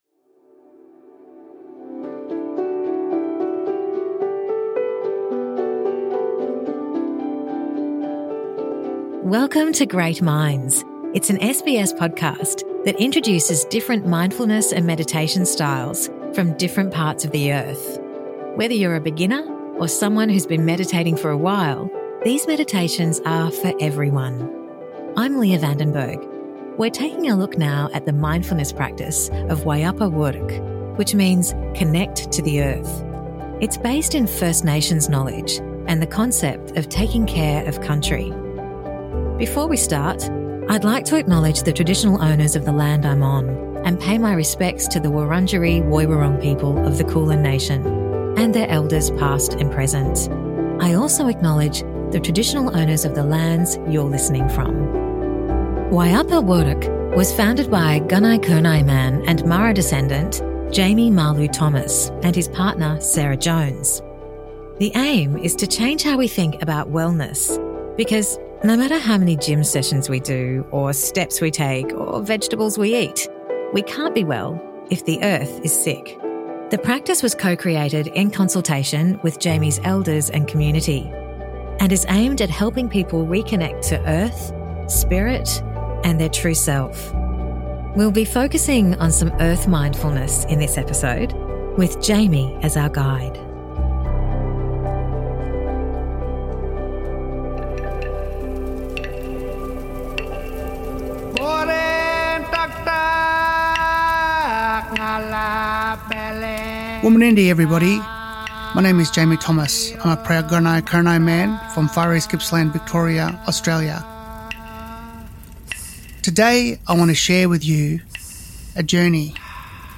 Wayapa Wuurrk meditation 2: Earth mindfulness
You can listen to this Great Minds meditation wherever you are.